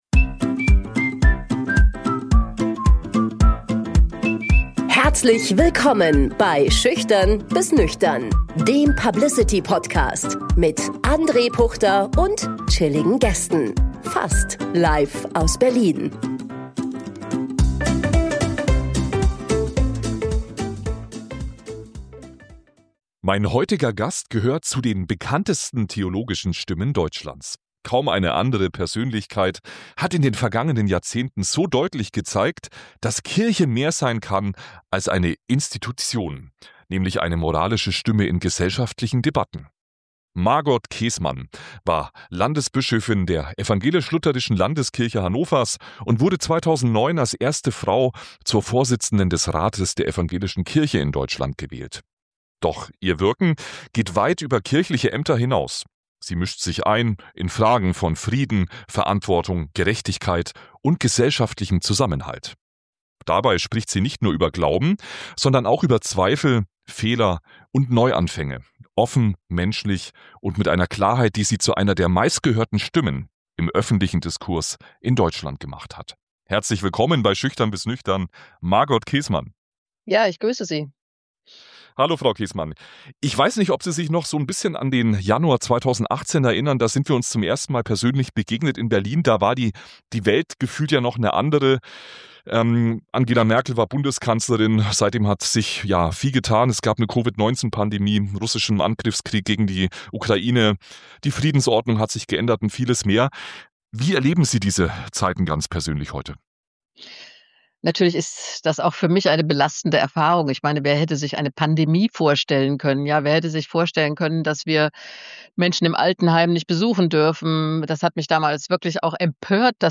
Ein Gespräch über Verantwortung, Zweifel, Hoffnung – und den Mut, auch unbequeme Positionen zu vertreten.